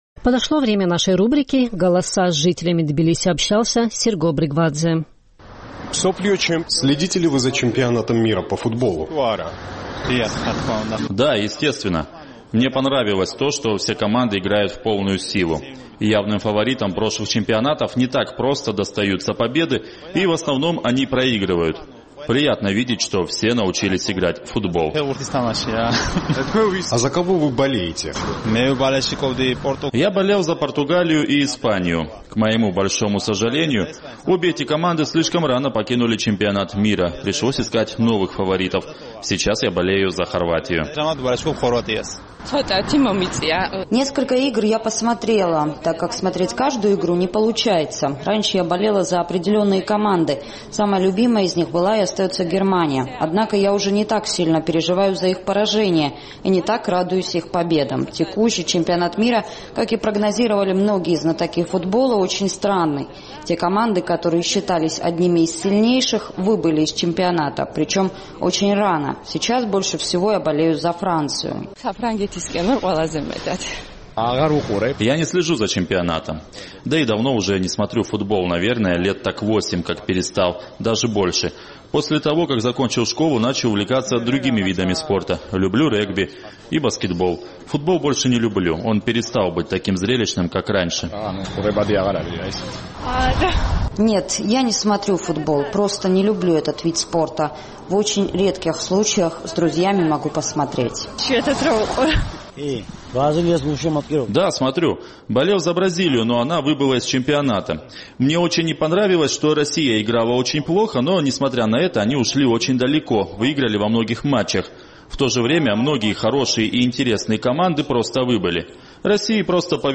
Чемпионат мира по футболу FIFA завершается 15 июля. Наш тбилисский корреспондент поинтересовался у местных жителей, следят ли они за матчами и за кого болеют.